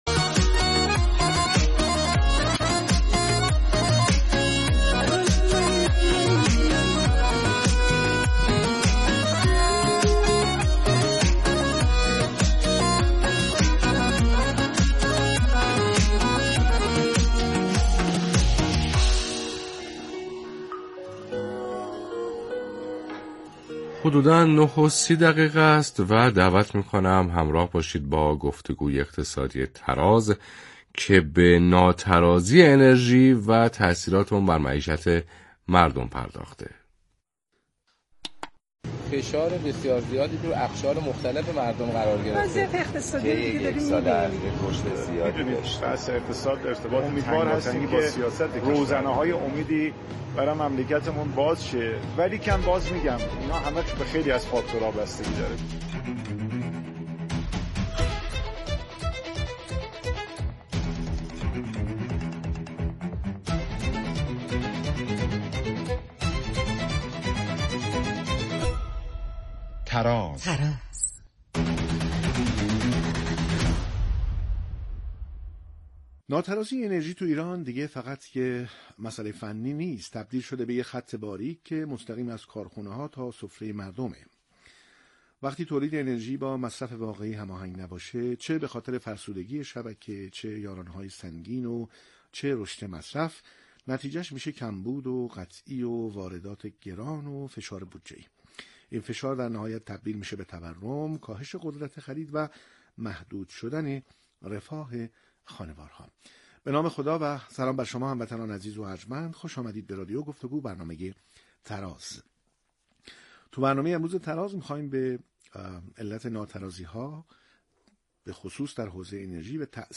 گفتگوی زنده خبری